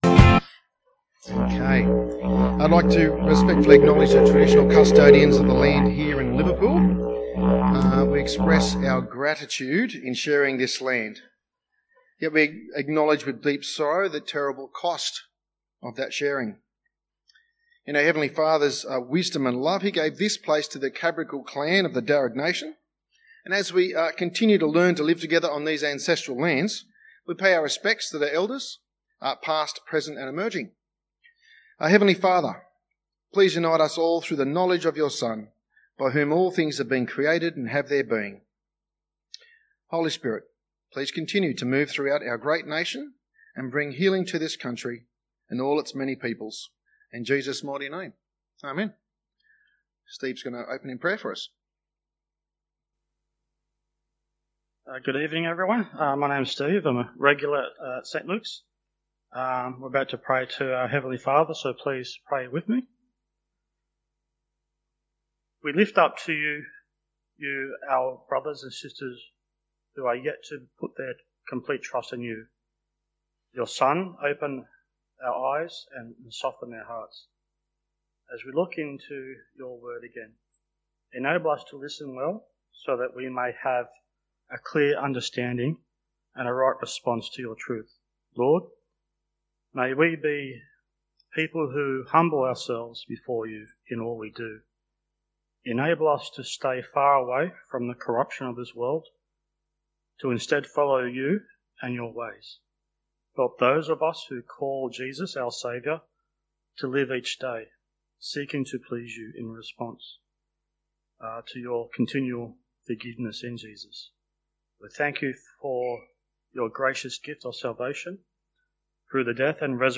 People-are-slaves-to-whatever-has-mastered-them-2-Peter-talk-3-WaYout-119.mp3